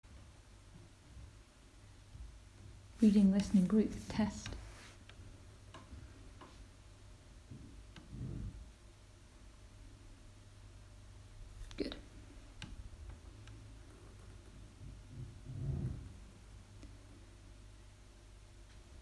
Live from Soundcamp: Wavefarm (Audio) Jun 20, 2023 shows Live from Soundcamp Live from London. The Reading~Listening group meet monthly as part of the Spree~Channelsea Radio Group.